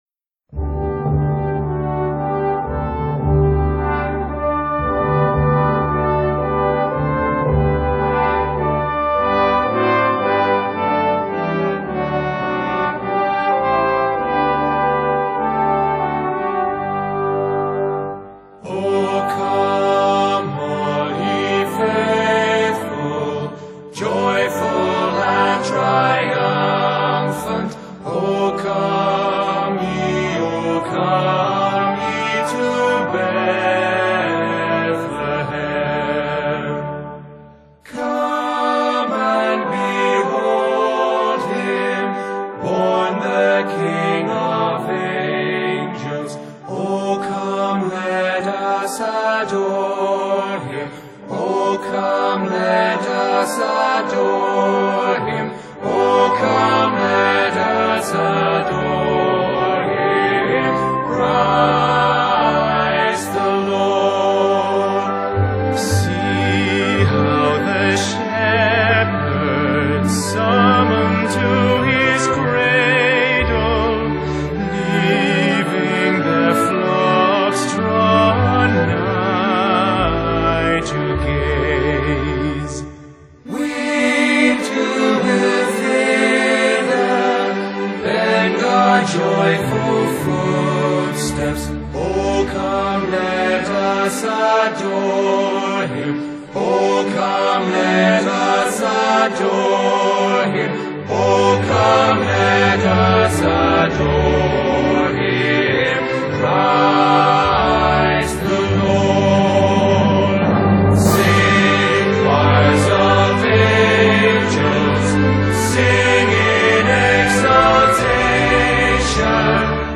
★永恆的祝福，美聲傳頌歡樂溫馨
以演唱愛爾蘭傳統音樂為主的人聲團體
把傳統加入現代流行音樂的節奏與電子伴奏